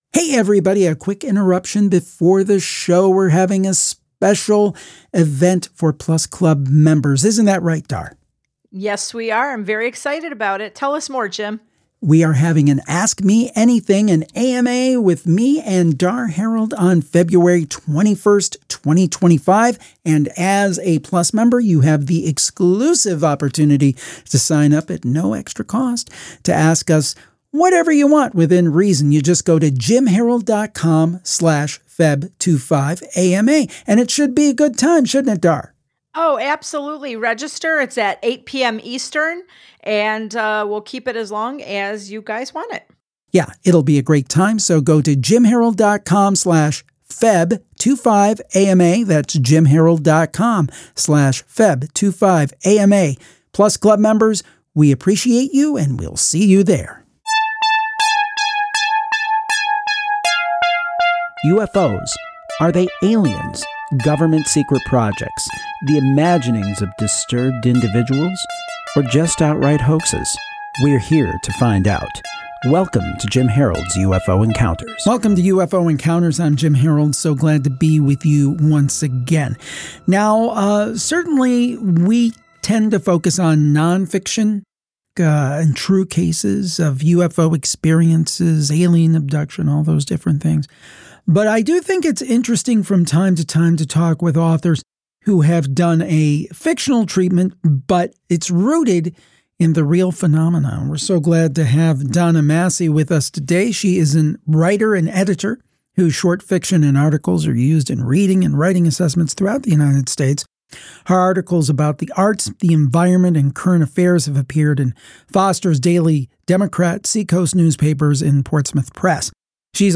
Audio Interviews